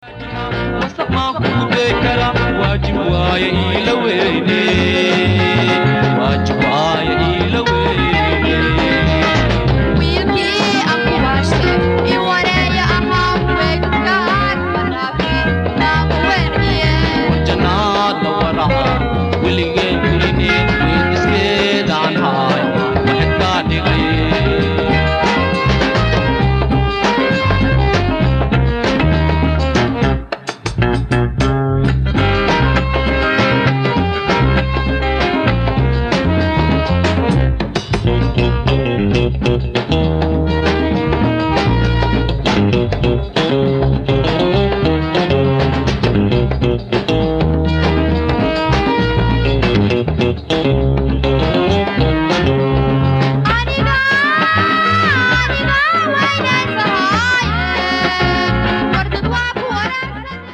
Digitized from cassettes